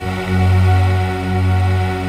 Index of /90_sSampleCDs/Optical Media International - Sonic Images Library/SI1_Breath Choir/SI1_Soft Breath